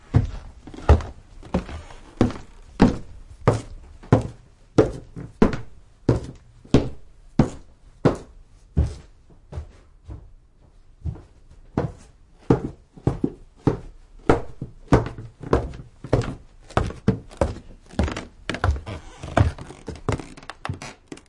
描述：当我来回移动重心时，地板会发出一些吱吱声。用Rode NTG2麦克风通过Canon DV摄像机录制，在Cool Edit Pro中编辑。
标签： 吱吱 叽叽嘎嘎 地板 脚步 硬木 硬木地板 吱吱声 吱吱响 行走
声道立体声